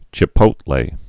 (chə-pōtlā)